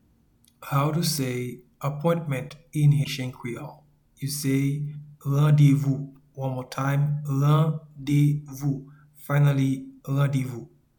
Appointment-in-Haitian-Creole-Randevou.mp3